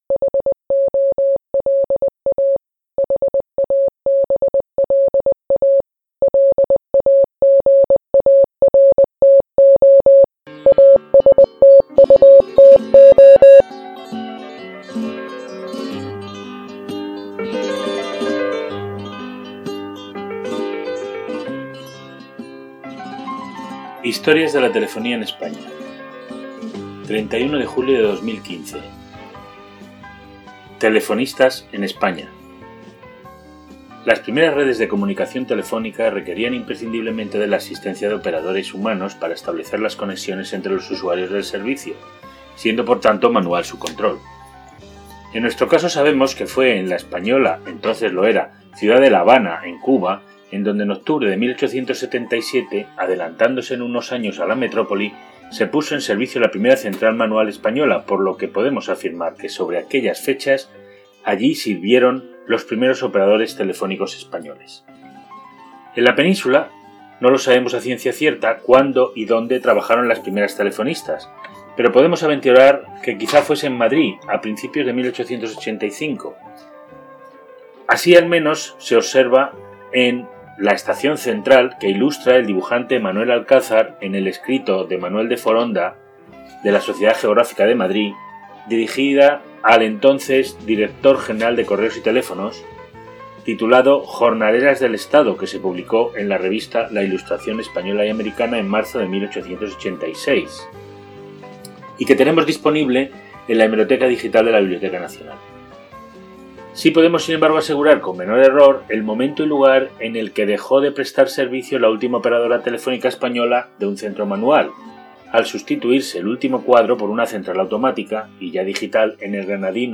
Animados a seguir por esta línea, y aprovechando el aniversario y como humilde celebración, estamos pensando en enriquecer algunas de nuestras entradas, añadiendo una grabación en audio del texto de las mismas, que facilite el acceso a su contenido para cualquiera que en un momento dado quiera «escucharlo».